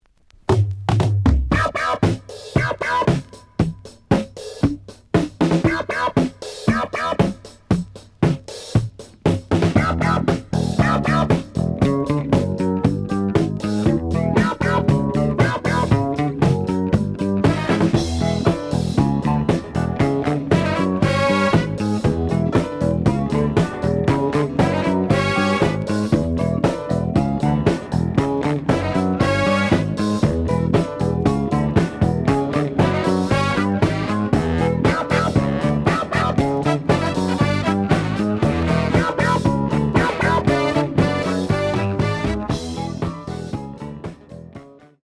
(Part 2) (Instrumental)
The audio sample is recorded from the actual item.
●Genre: Disco